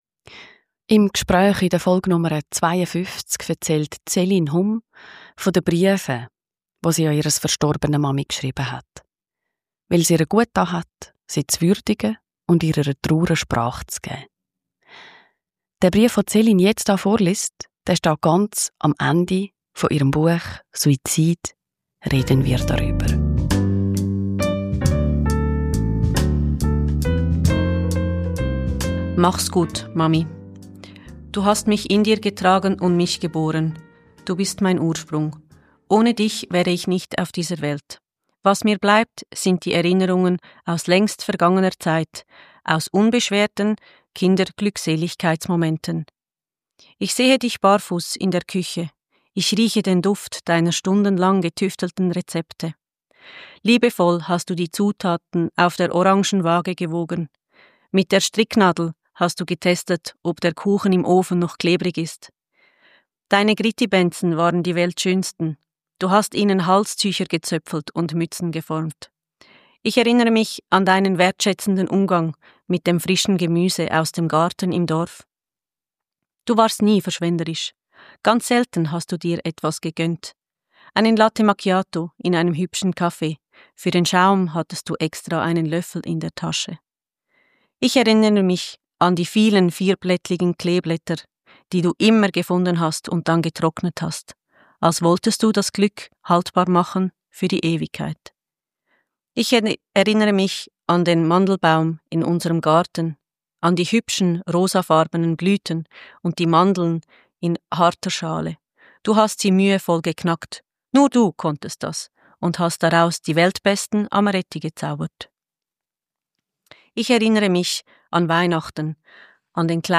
liest aus ihrem Buch